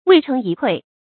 未成一篑 wèi chéng yī kuì
未成一篑发音
成语注音ㄨㄟˋ ㄔㄥˊ ㄧ ㄎㄨㄟˋ